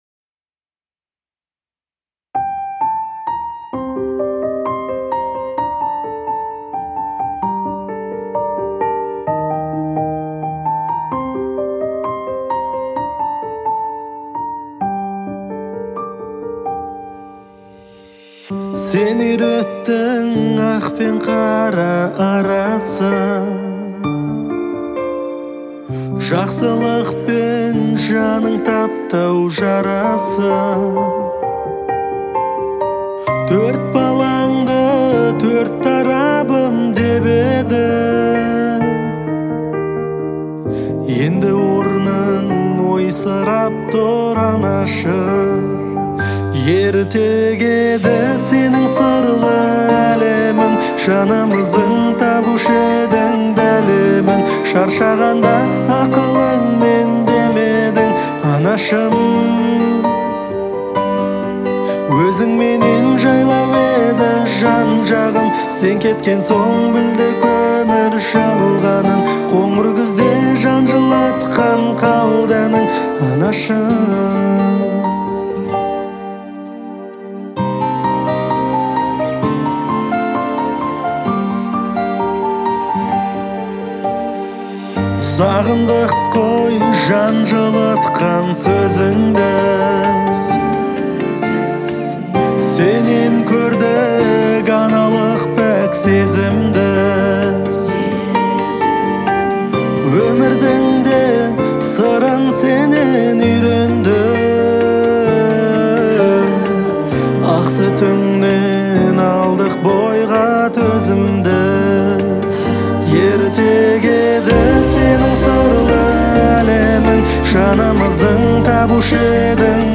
это трогательное произведение в жанре казахской поп-музыки